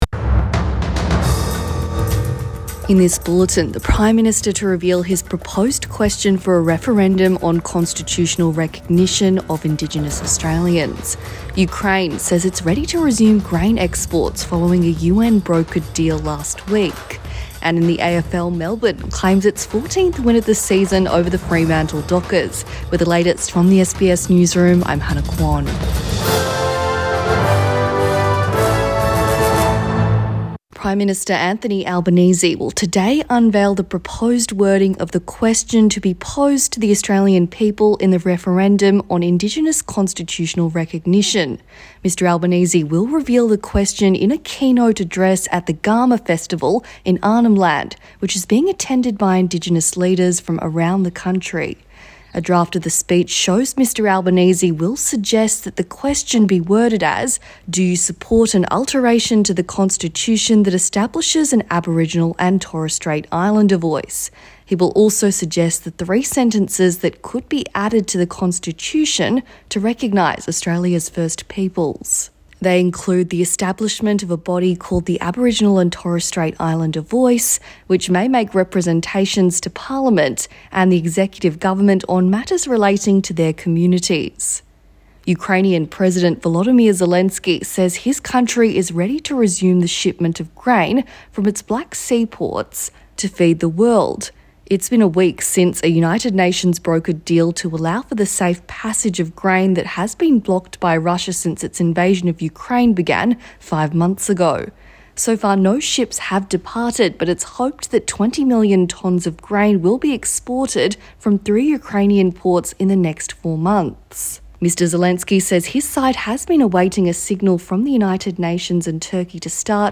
AM bulletin 30 July 2022